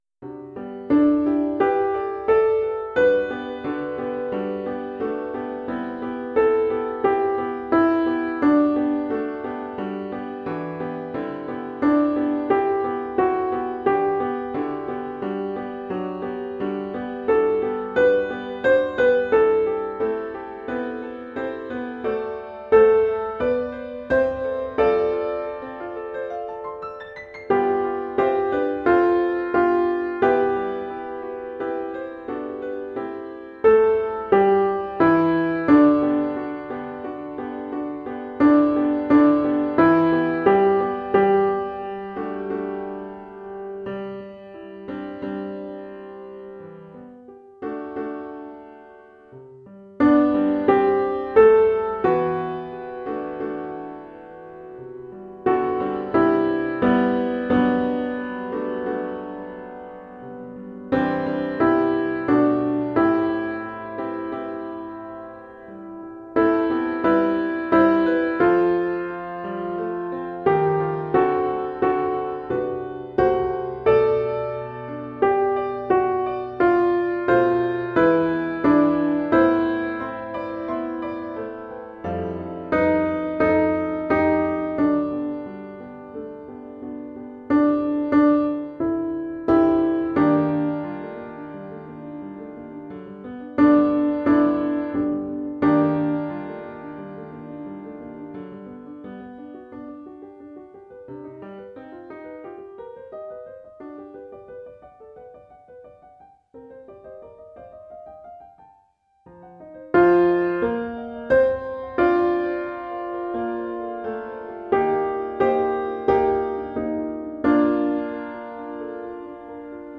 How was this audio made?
Lockdown Practice Recordings